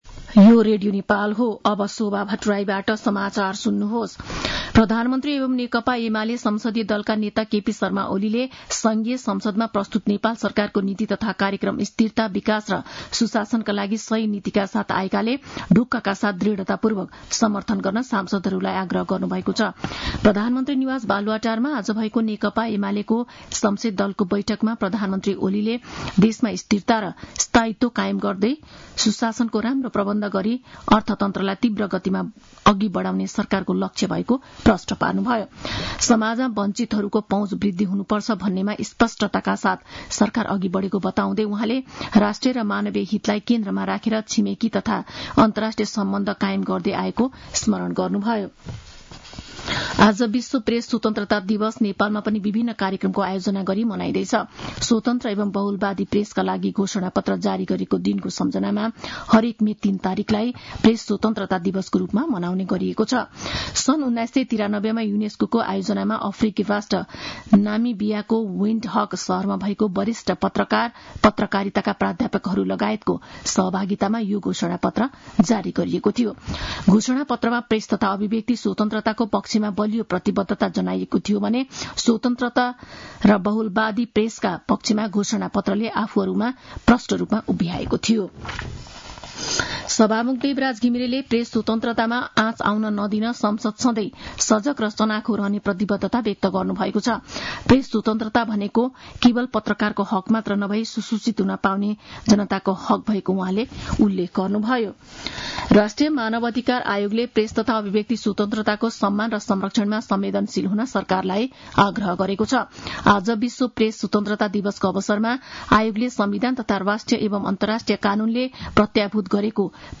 साँझ ५ बजेको नेपाली समाचार : २० वैशाख , २०८२